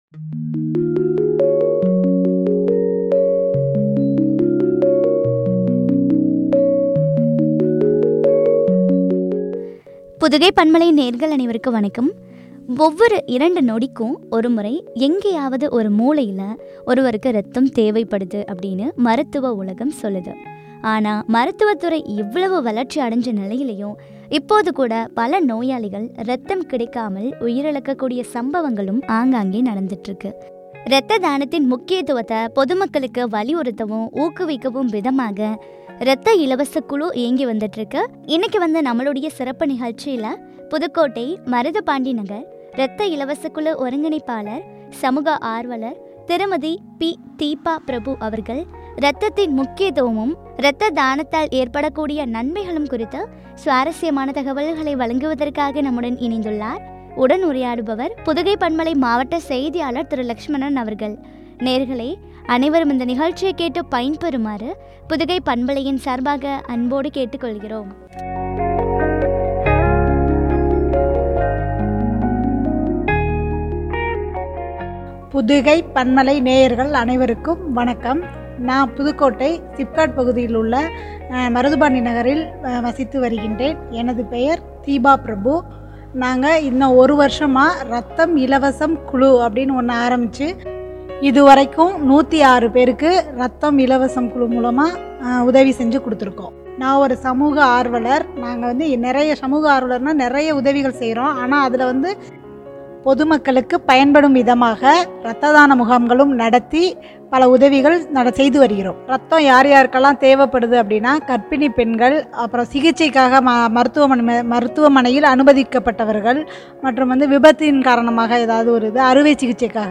” உயிர் காக்கும் உன்னதமான தானம் ” குறித்து வழங்க உரையாடல்.